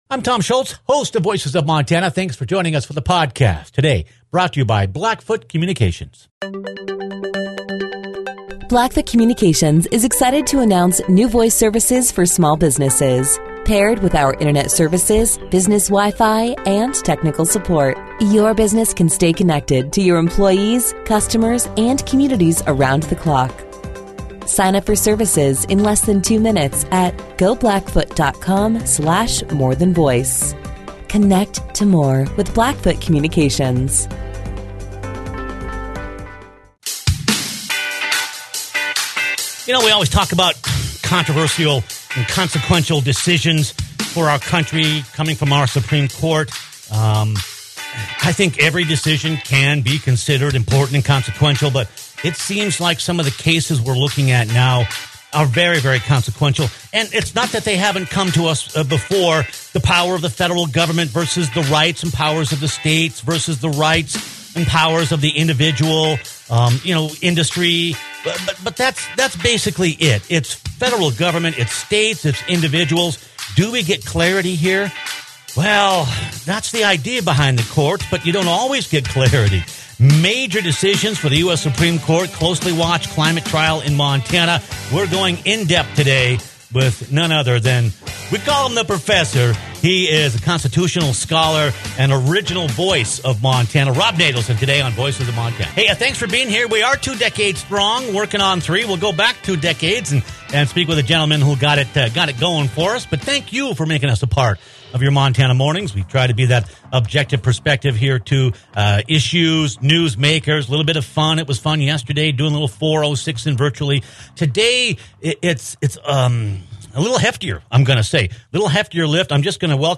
With recent major decisions by the U.S. Supreme Court, and a closely-watched climate trial in Montana, the courts will have had a lot to say about the direction of our country. Join us for an in-depth discussion on the impact of the Supreme Court rulings on the power of the federal government, and a look